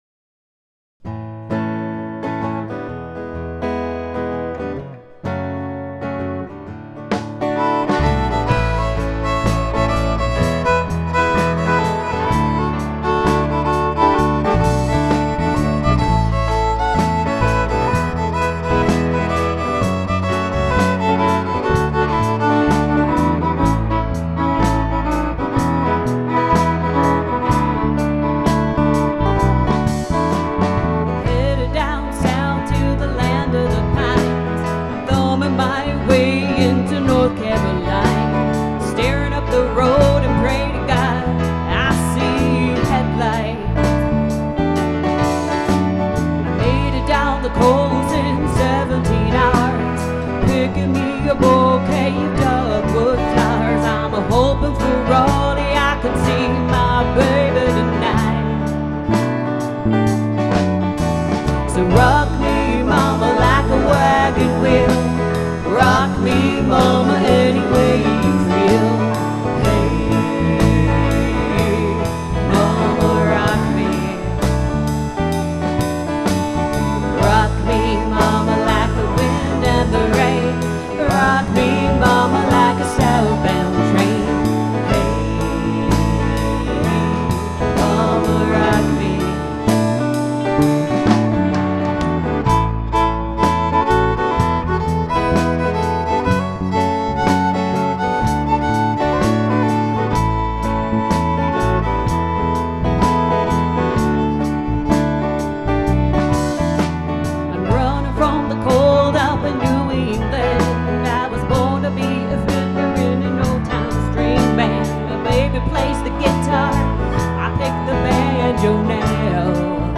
first studio album
Mason & Hamlin grand piano
a little Honky-tonk
close harmonies